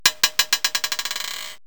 marbldrp.mp3